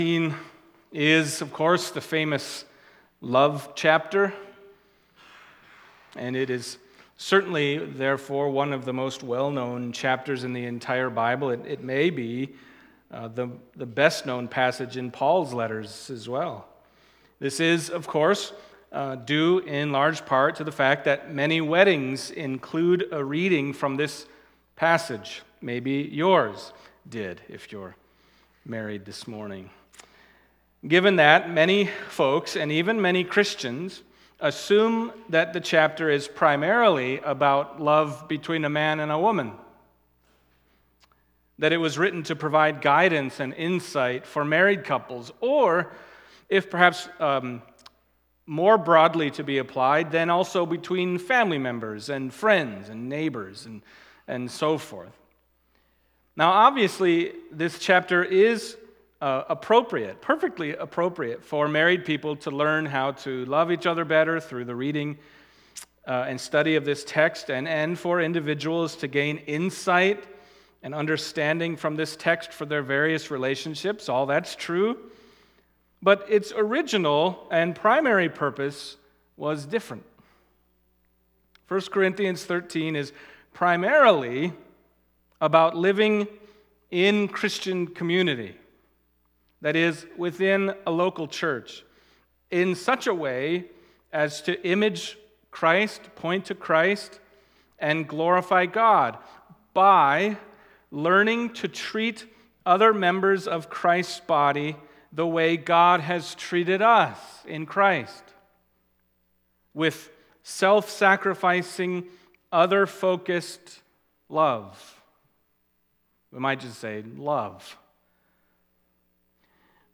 1 Corinthians Passage: 1 Corinthians 13:1-13 Service Type: Sunday Morning 1 Corinthians 13:1-13 « The Body of Christ We Have Seen His Glory…